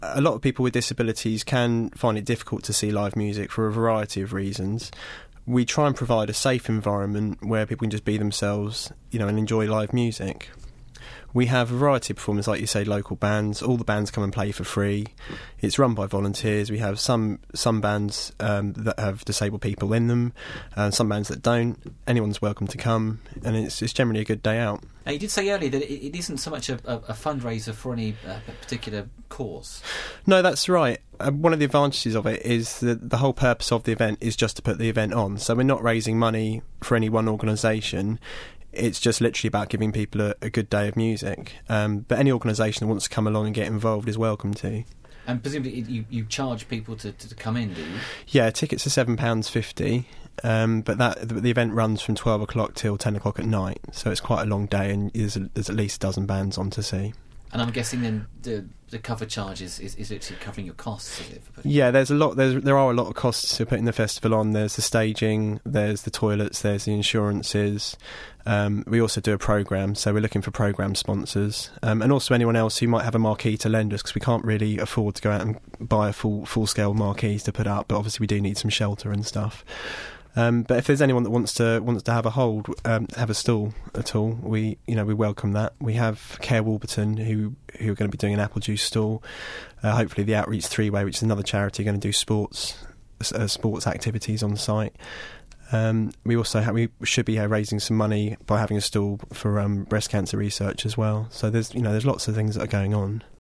Apulstock-Radio-Interview.mp3